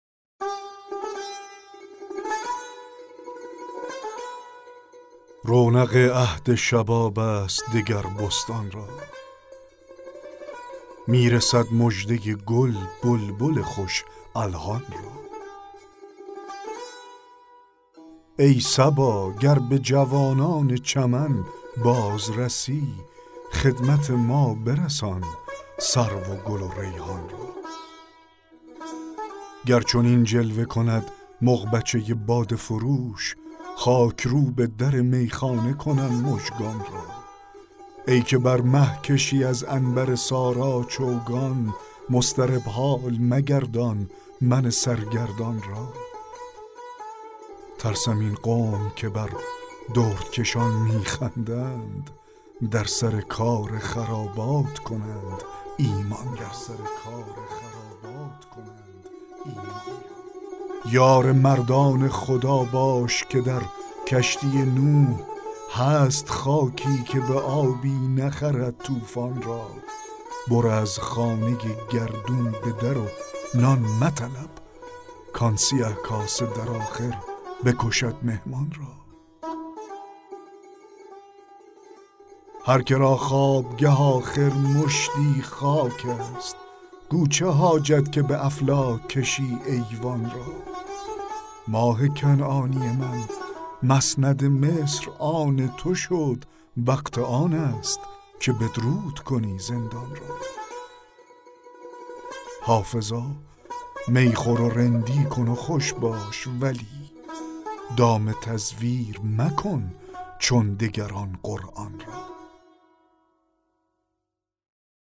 دکلمه غزل 9 حافظ
دکلمه غزل رونق عهد شباب است دگر بستان را